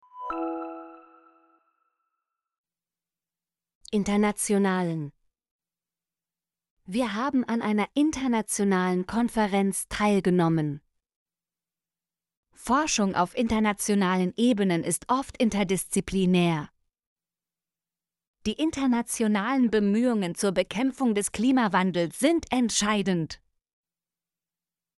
internationalen - Example Sentences & Pronunciation, German Frequency List